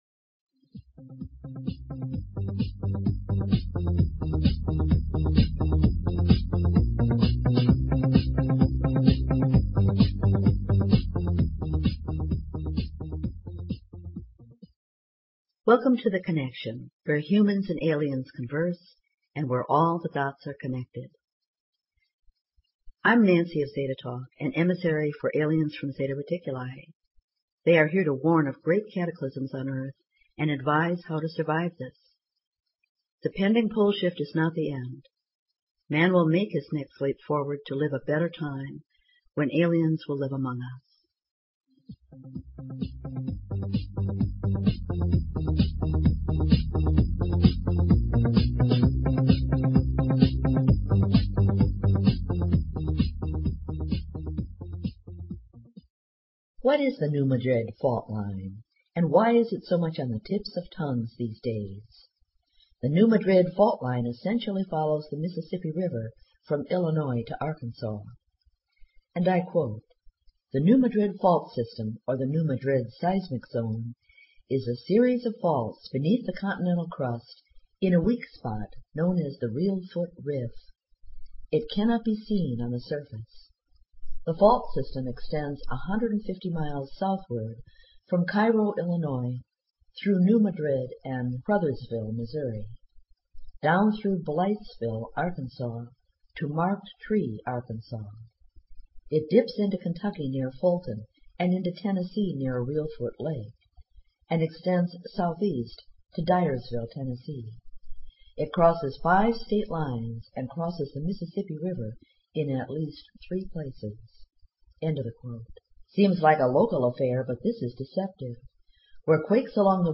Talk Show Episode, Audio Podcast, The_Connection and Courtesy of BBS Radio on , show guests , about , categorized as